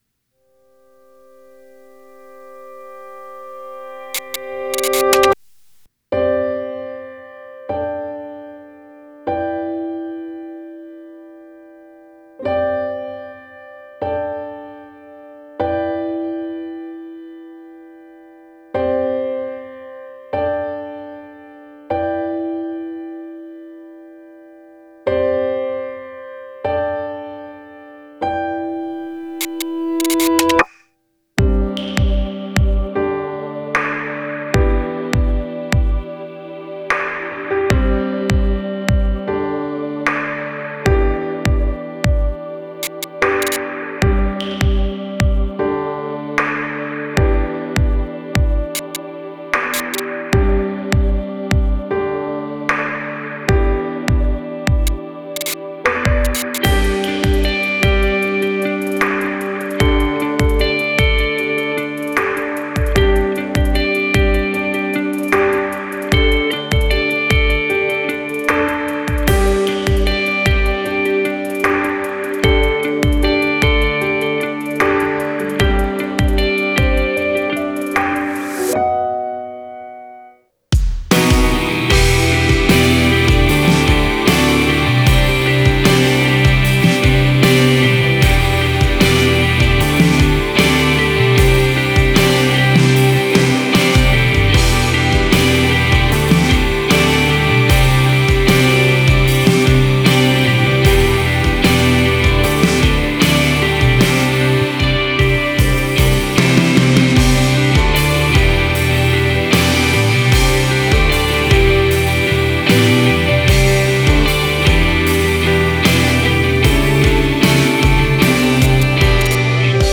オリジナルKey：「G